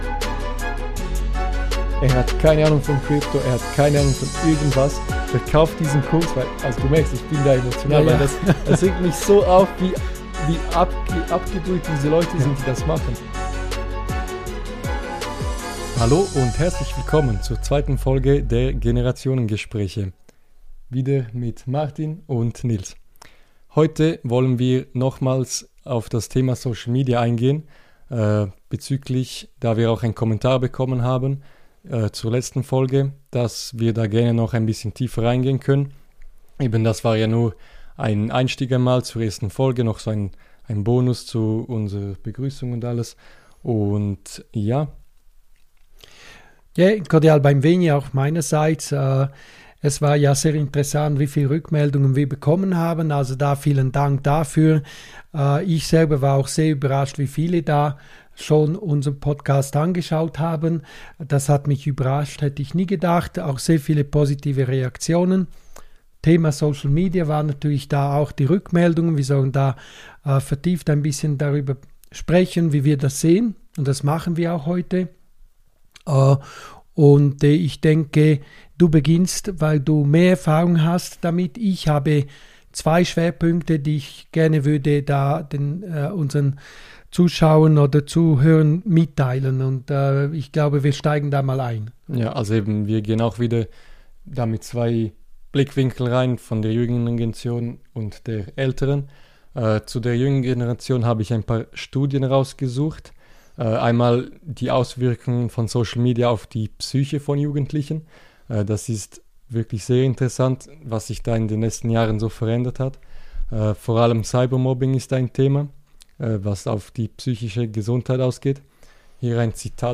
Wir sprechen offen über die psychischen Auswirkungen von Plattformen wie TikTok, Instagram & Co., insbesondere auf Jugendliche – aus der Perspektive zweier Generationen.